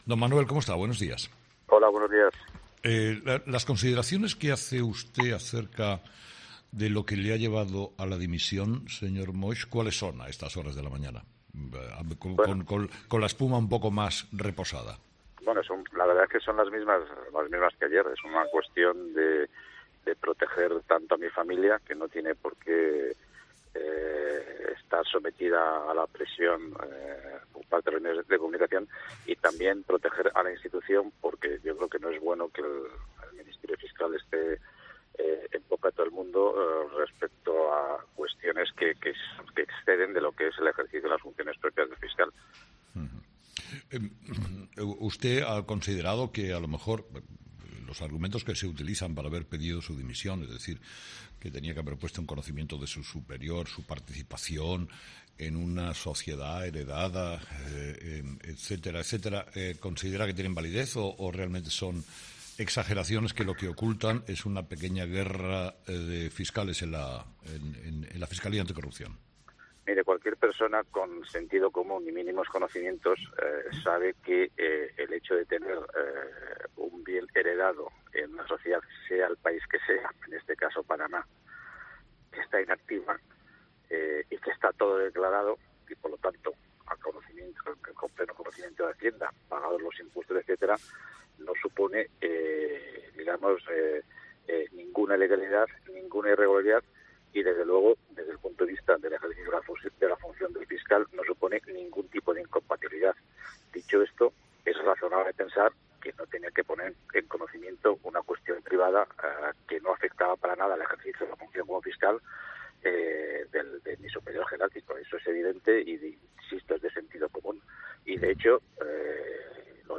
En su primera entrevista tras su dimisión como fiscal jefe Anticorrupción insiste en que "no hay irregularidad" en tener una sociedad en Panamá